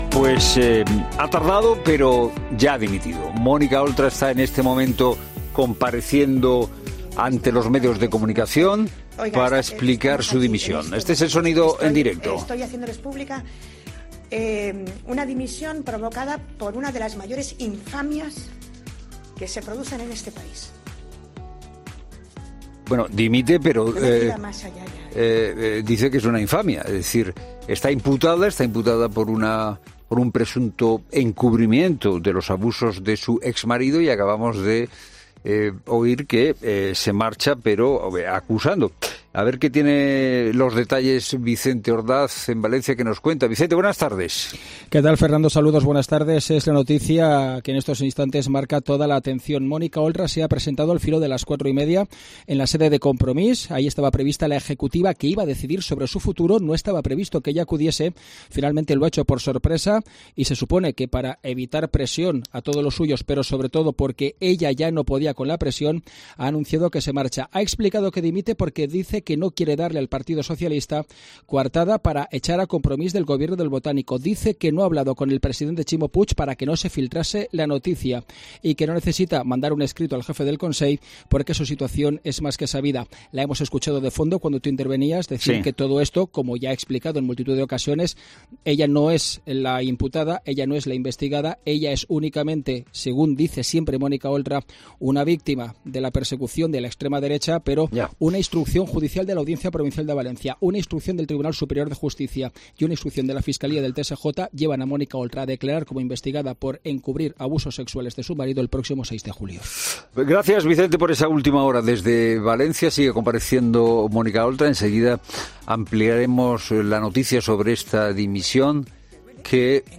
La vicepresidenta del Gobierno valenciano y coportavoz de Compromís, Mónica Oltra, ha anunciado este martes en rueda de prensa que dimite como miembro del Consell y de Les Corts
La vicepresidenta del Consell, Mónica Oltra, ha anunciado entre lágrimas que deja su cargo en el gobierno valenciano y de Les Corts tras su imputación en el caso del presunto encubrimiento de los abusos de su exmarido a una menor tutelada.